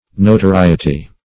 Notoriety \No`to*ri"e*ty\ (n[=o]`t[-o]*r[imac]"[-e]*t[y^]), n.